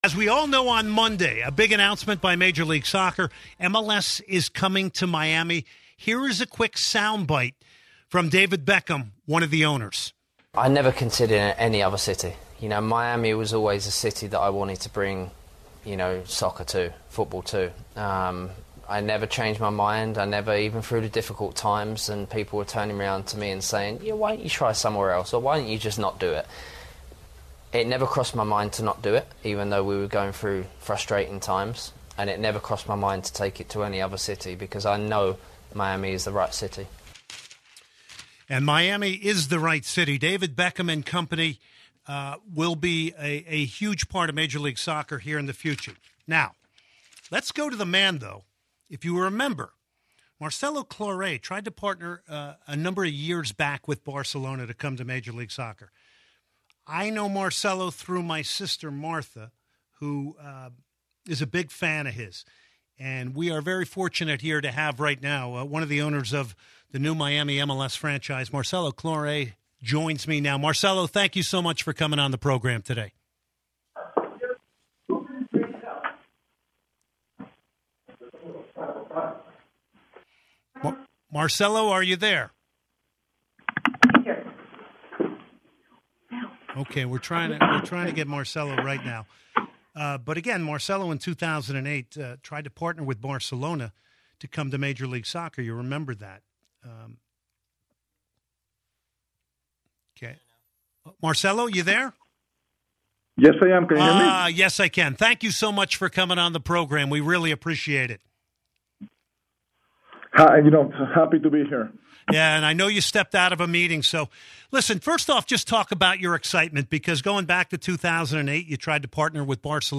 1.31.2018 Soccer Matters Marcelo Claure Interview